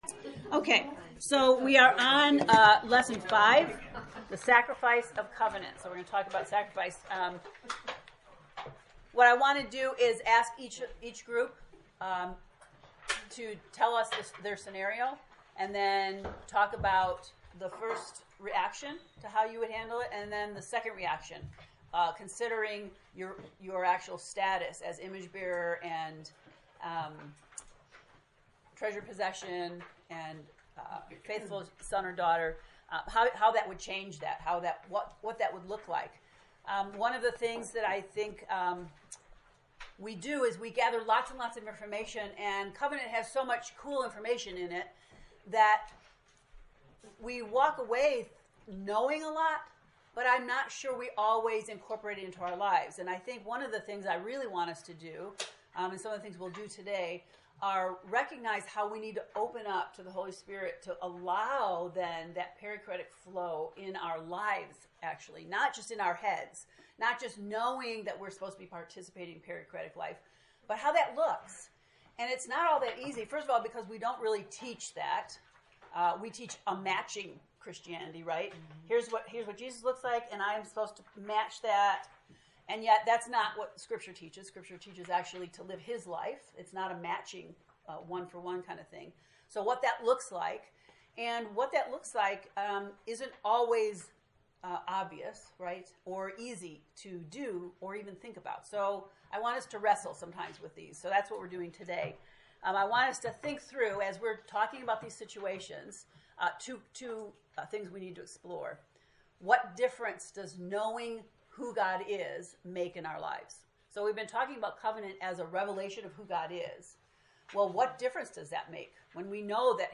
How do we practically live a “holy life”? Today’s lesson will involve wrestling with some real-life issues so we can begin to be open to practicing “dying to self.” The first part of the lecture involves our study groups taking on specific scenarios and talking through responses.